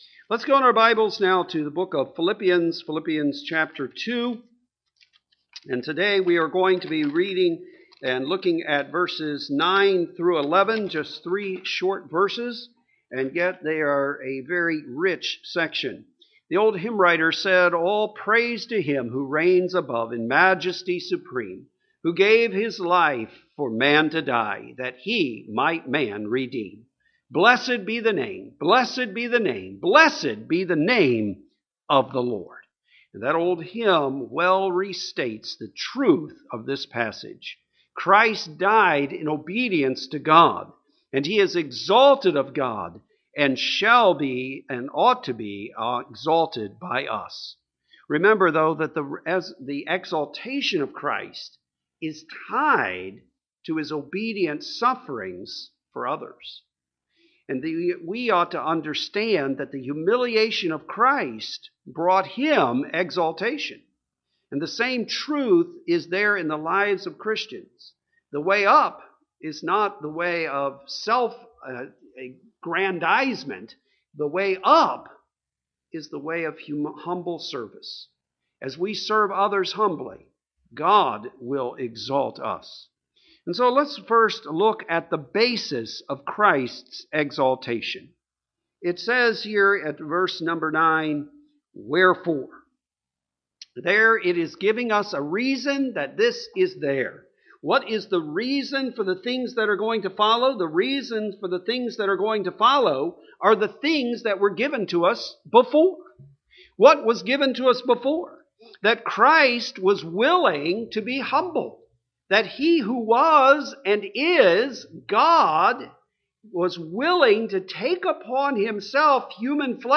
Philippians 2 (5/17) Sunday AM